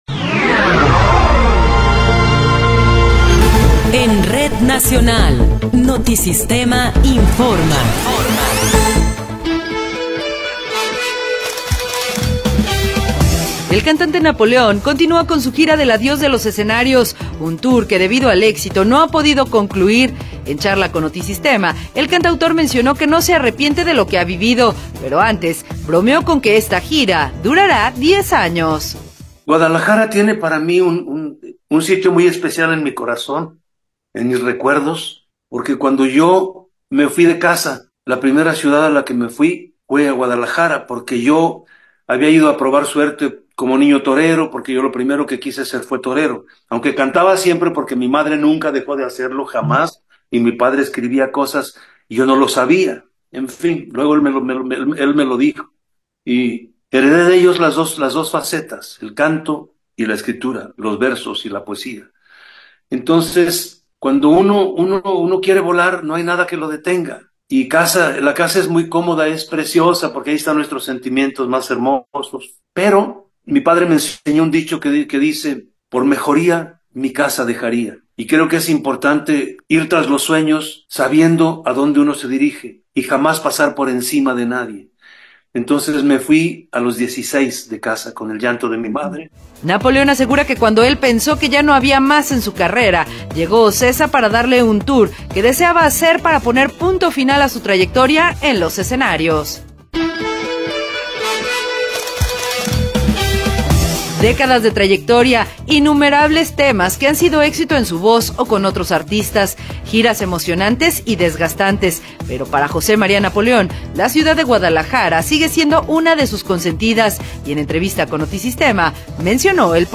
Noticiero 10 hrs. – 17 de Marzo de 2024
Resumen informativo Notisistema, la mejor y más completa información cada hora en la hora.